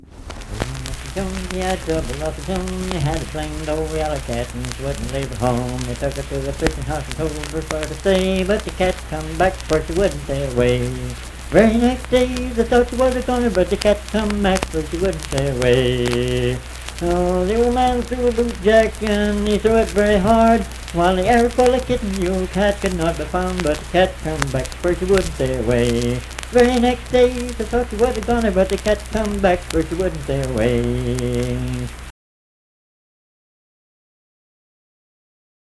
Cat Came Back - West Virginia Folk Music | WVU Libraries
Unaccompanied vocal music
Performed in Dundon, Clay County, WV.
Voice (sung)